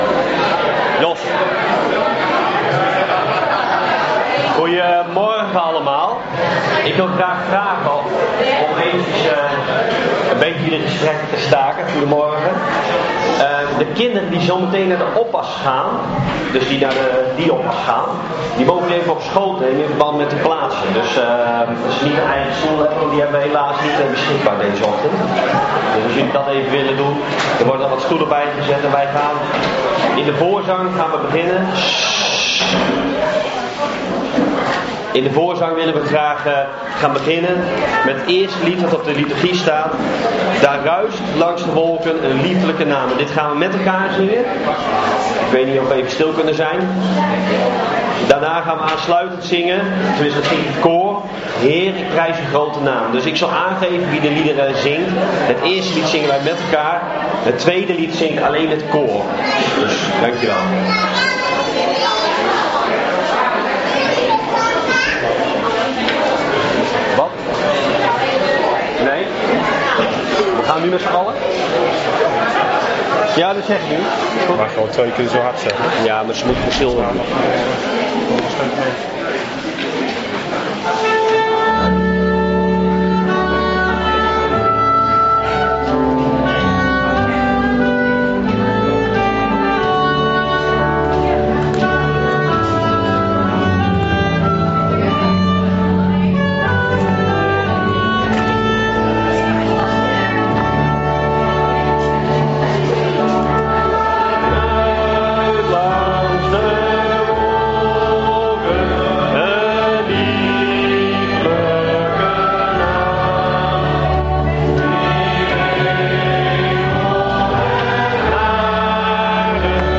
Johannes 1:(Gehele dienst, preek start bij 0:58)